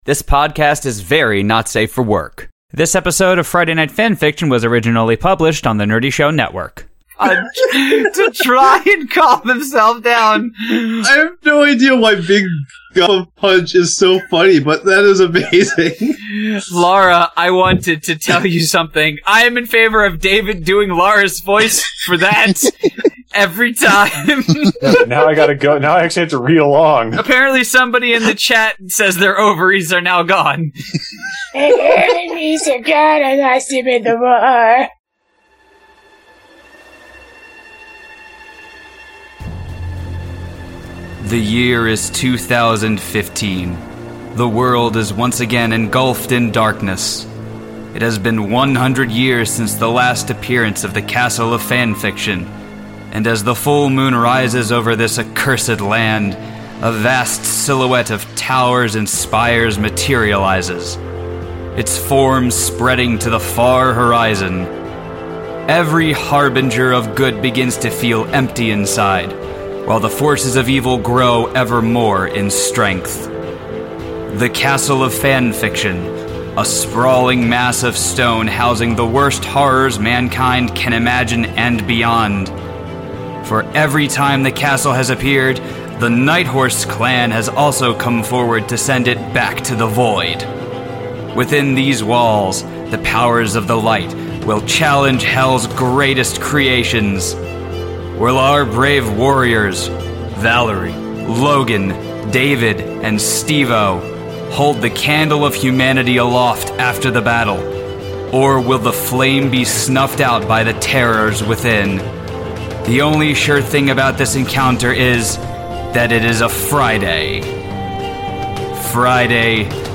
We started reading more of what nearly killed us last time.